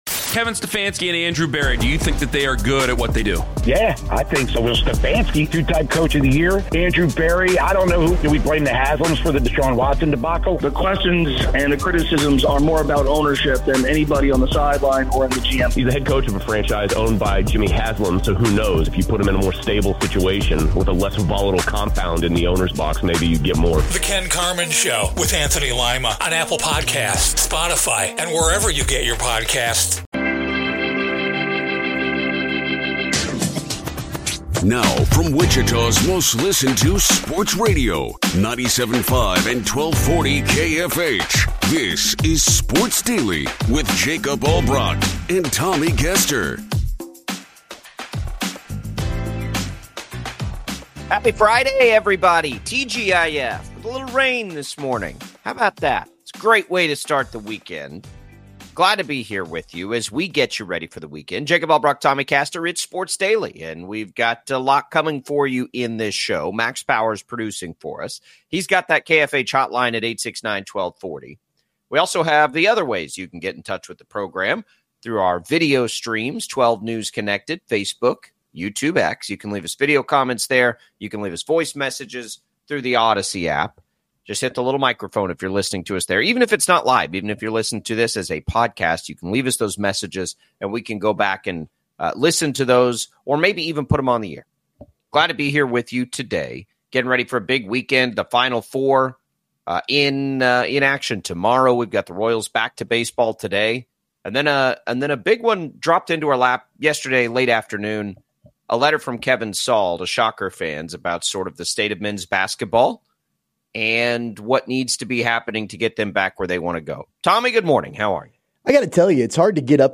Wichita's #1 afternoon talk show.